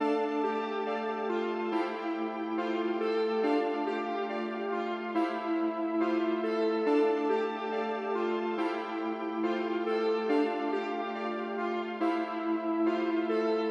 扑克彻牌
标签： 二十一点 扑克 家庭录音 咔嚓
声道立体声